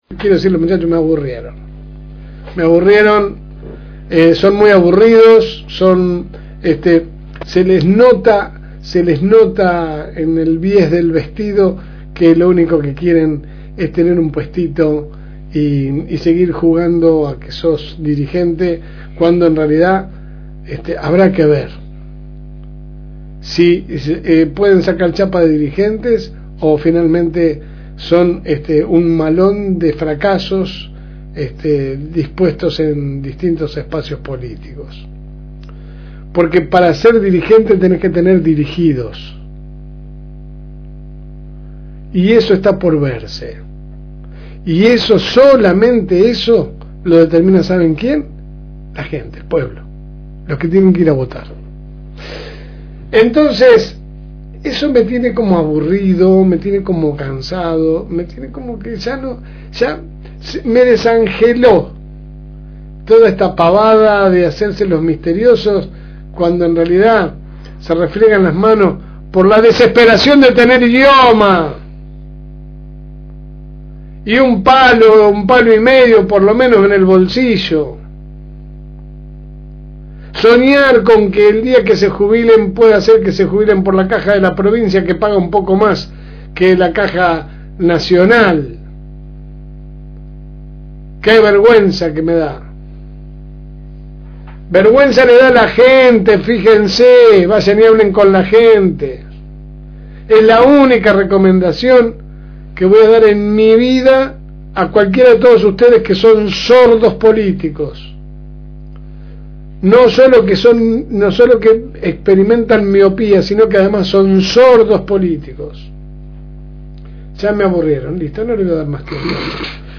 AUDIO – Pequeña reflexión – FM Reencuentro